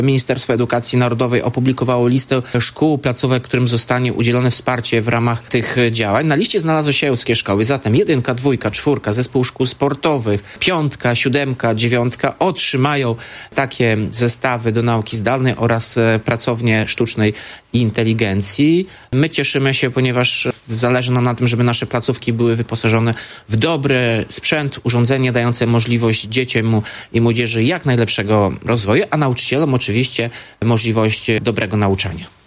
– Wśród nich są ełckie placówki – mówi Tomasz Andrukiewicz, prezydent miasta.